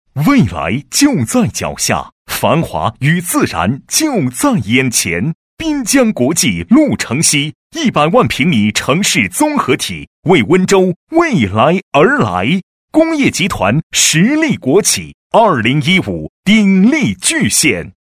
地产男175号（深圳实地）
年轻时尚 建筑房产 描述： 下载 现在咨询 房产男122号（凤凰城）